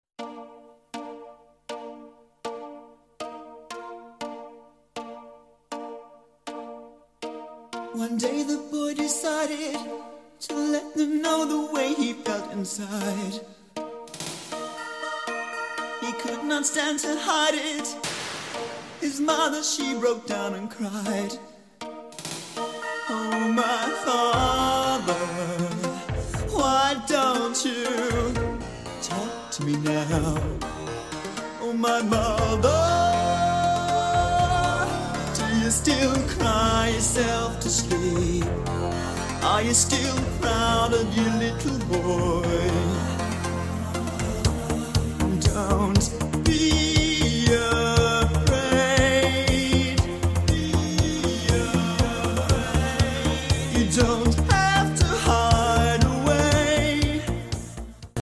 It was truth wrapped in synthesizers.